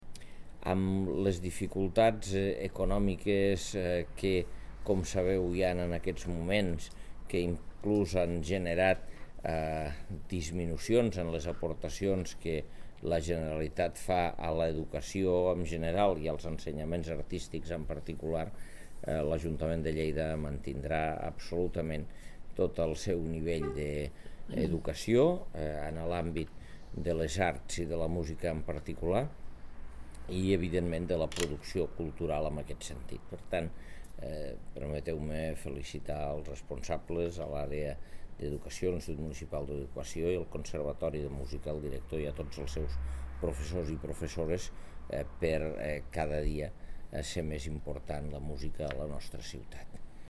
tall-de-veu-de-lalcalde-angel-ros-sobre-el-pressupost-de-la-paeria-en-educacio